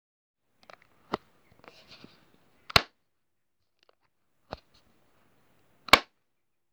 Ik maak het geluid 2x
Ja! Het openen en sluiten ervan